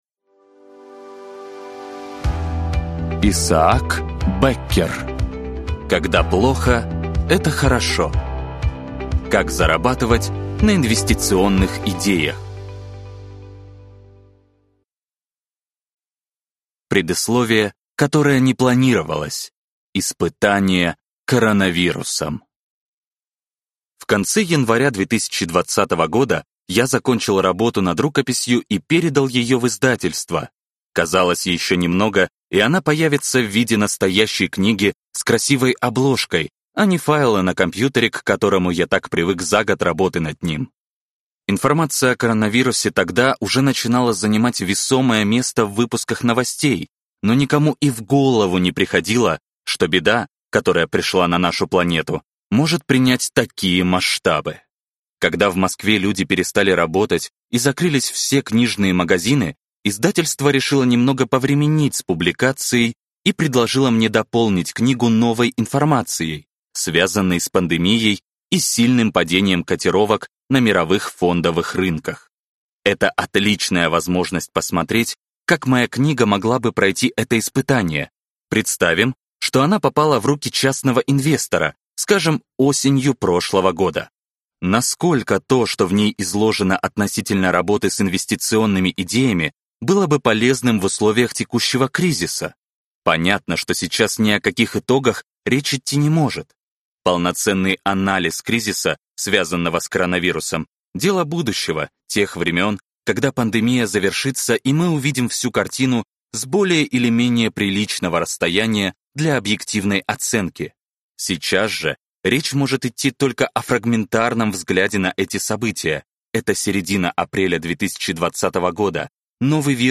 Аудиокнига Когда плохо – это хорошо | Библиотека аудиокниг